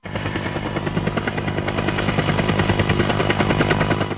دانلود آهنگ طیاره 5 از افکت صوتی حمل و نقل
جلوه های صوتی
دانلود صدای طیاره 5 از ساعد نیوز با لینک مستقیم و کیفیت بالا